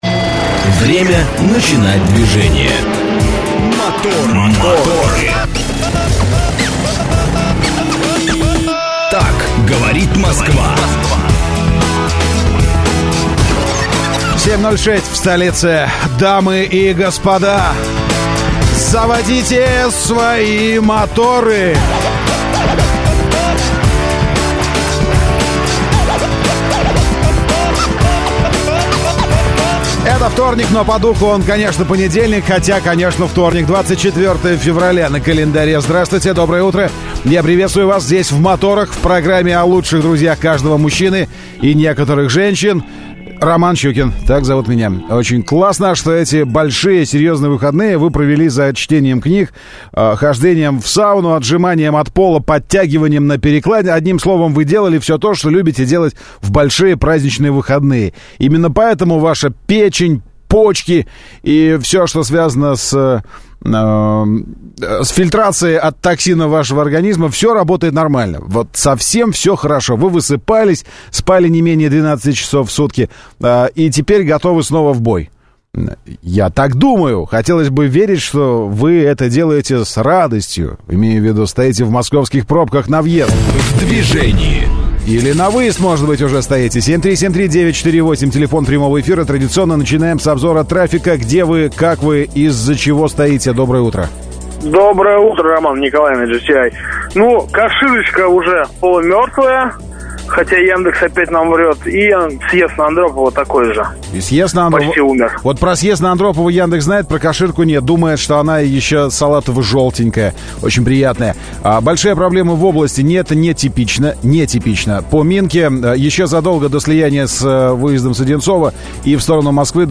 Утренняя программа для водителей и не только. Ведущие рассказывают о последних новостях автомобильного мира, проводят со слушателями интерактивные «краш-тесты» между популярными моделями одного класса, делятся впечатлениями от очередного тест-драйва.